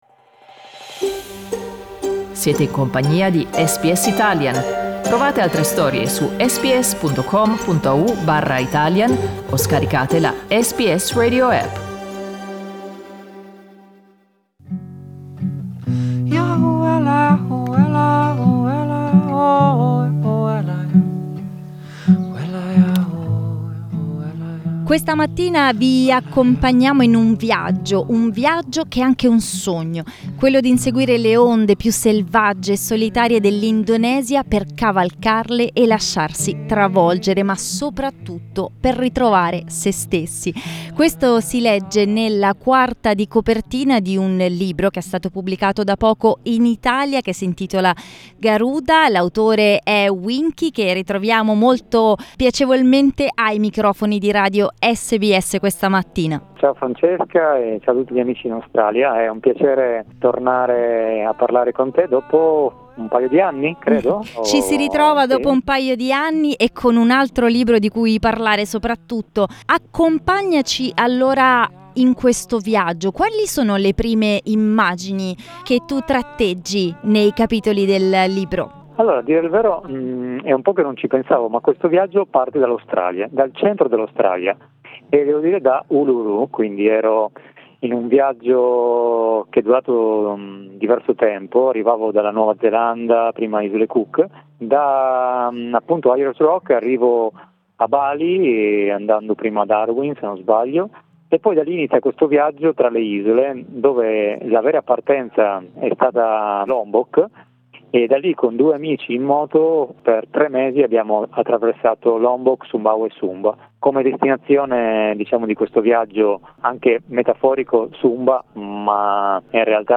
Listen to interview in Italian.